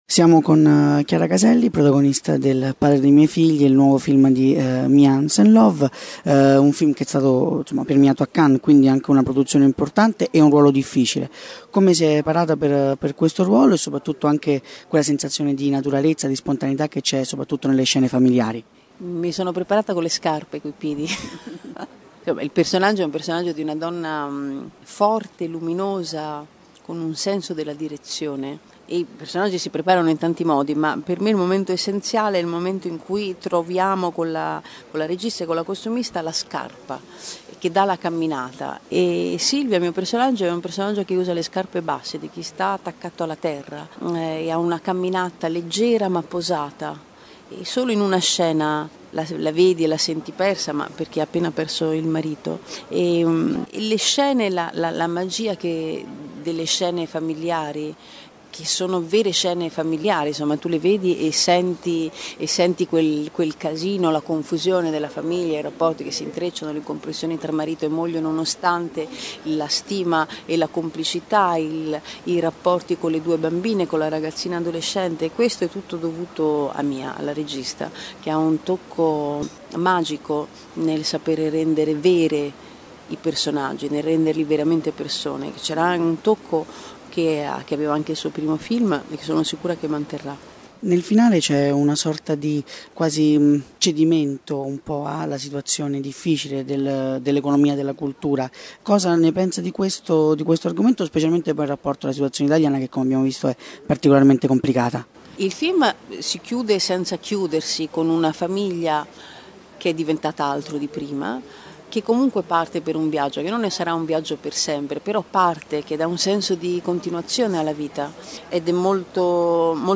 Intervista a Chiara Caselli | RadioCinema
Intervista_Chiara_Caselli_Il_padre_dei_miei_figli.mp3